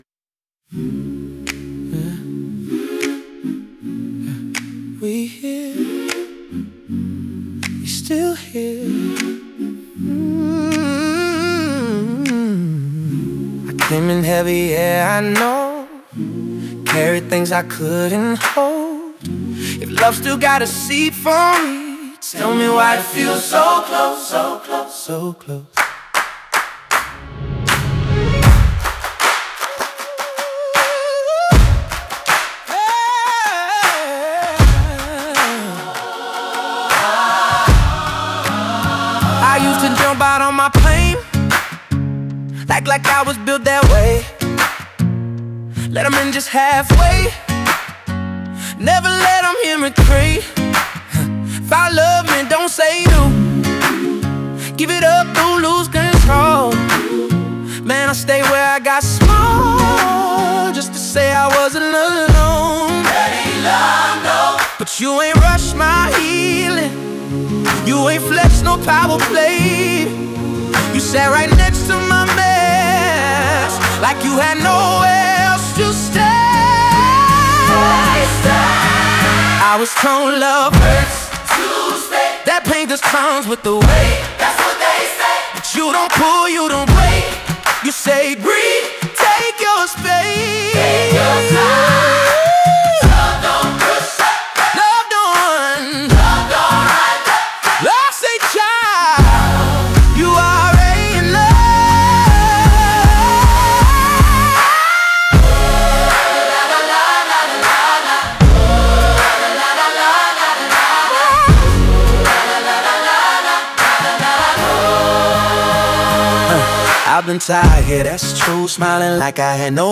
中盤からは合唱や手拍子を想起させる構造で、孤独だった視点が「共にいる感覚」へと広がっていきます。